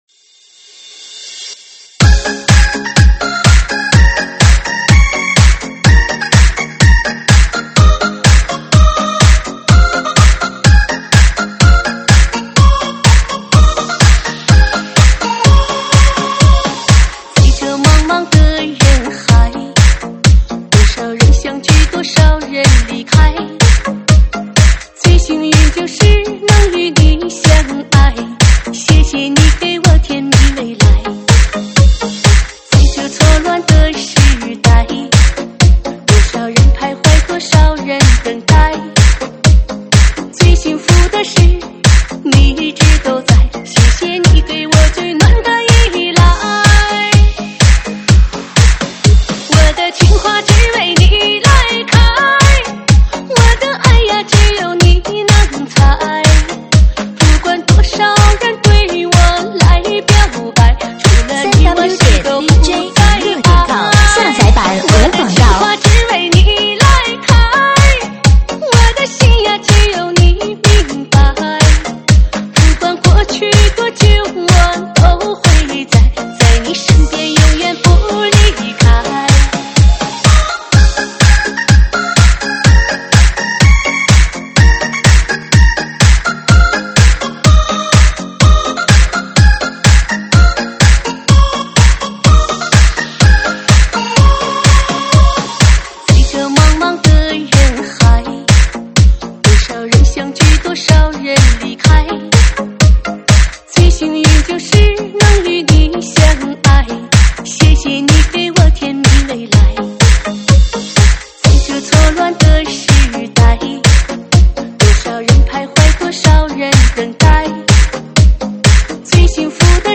舞曲类别：快四